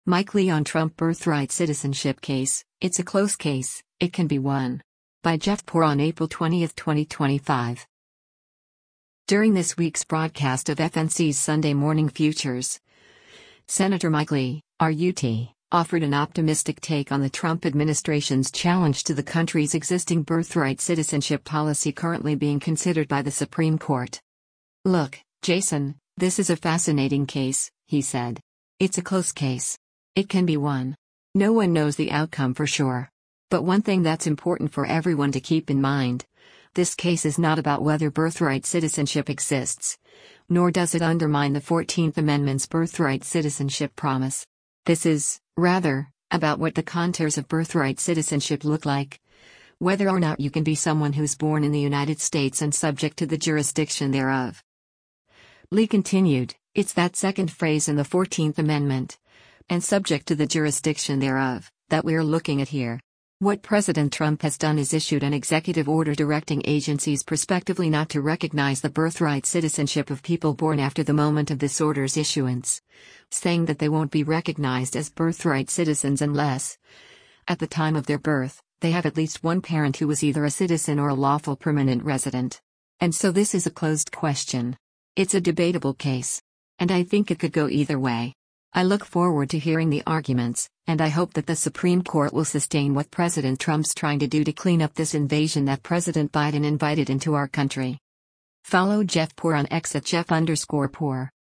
During this week’s broadcast of FNC’s “Sunday Morrning Futures,” Sen. Mike Lee (R-UT) offered an optimistic take on the Trump administration’s challenge to the country’s existing birthright citizenship policy currently being considered by the Supreme Court.